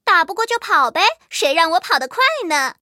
M18地狱猫司令部语音2.OGG